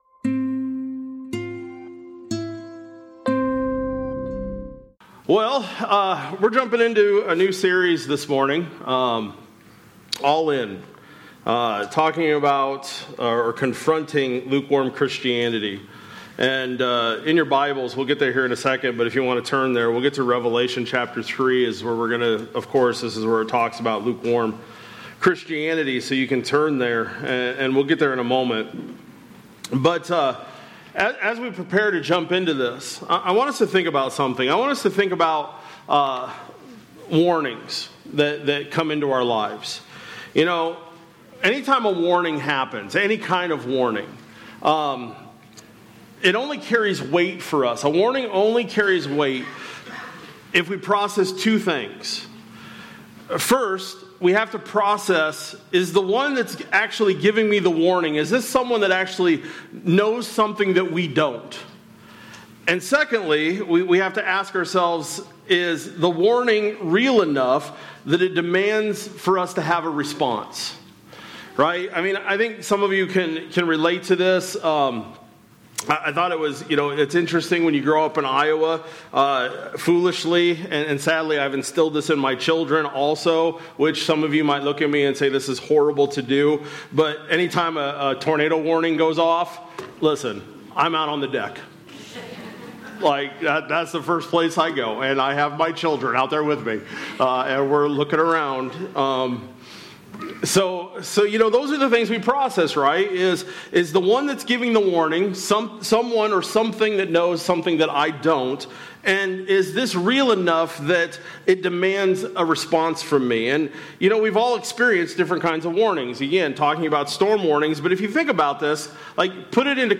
April-12-26-Sermon-Audio.mp3